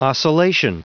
Prononciation du mot oscillation en anglais (fichier audio)
Prononciation du mot : oscillation